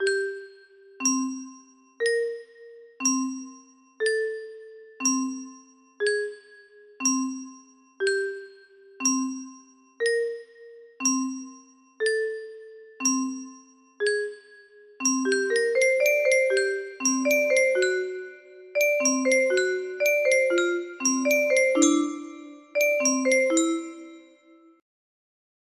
mapped out in exact rhythm 4/4 tempo 60